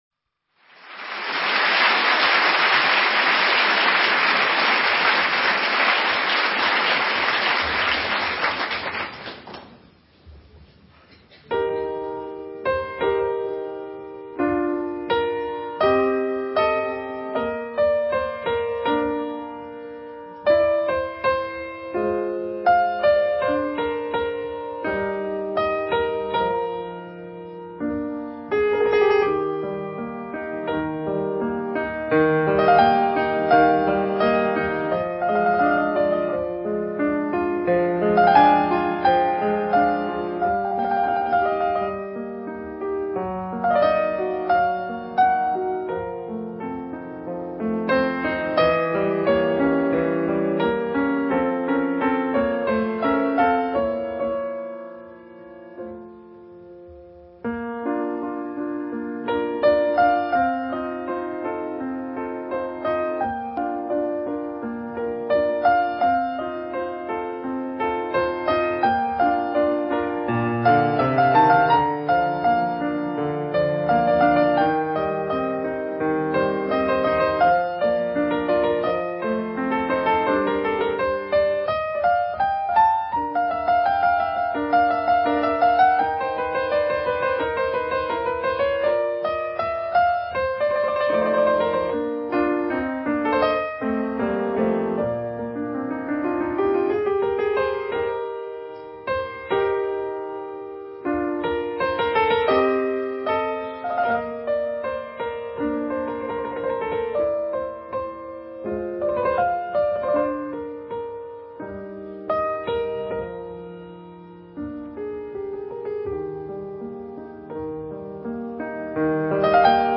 幻想曲 K475 (dvd轉mp3) Recorded live in 1981.